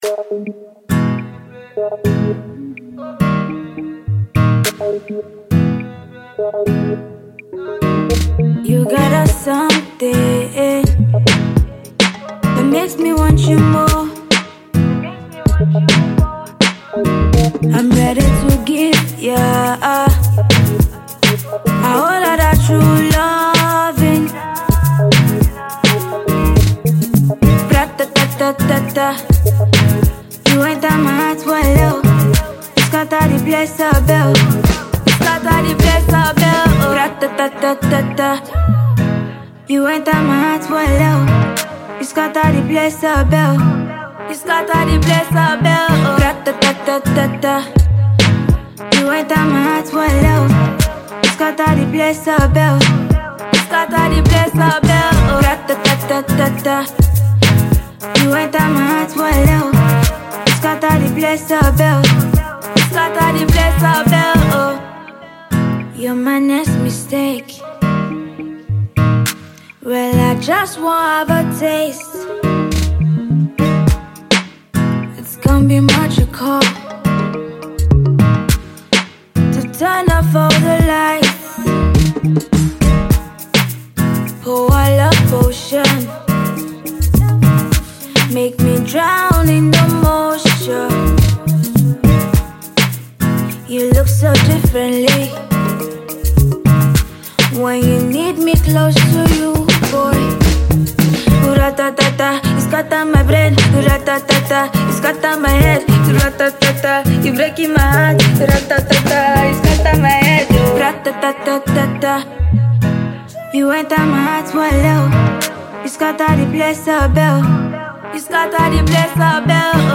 afropop-soul songstress